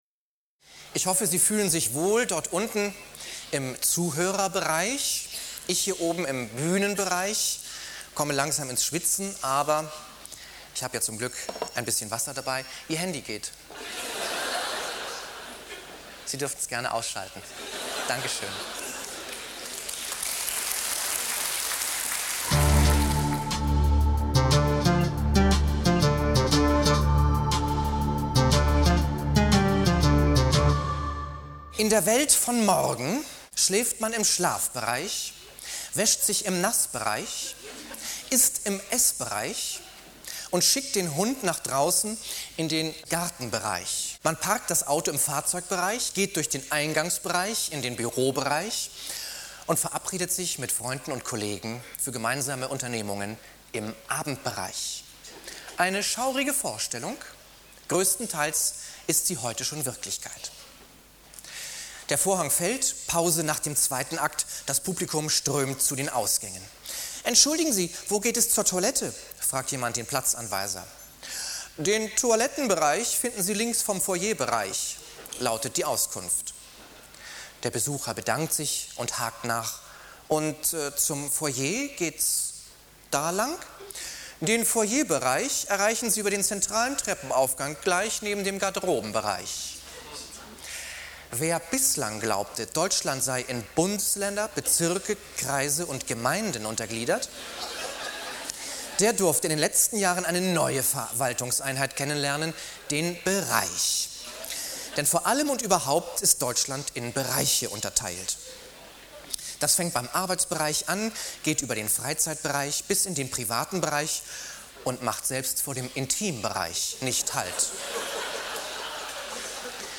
Auszug einer Live-Lesung auf Schloss Ippenburg bei Bad Essen vom 16. Juni 2005.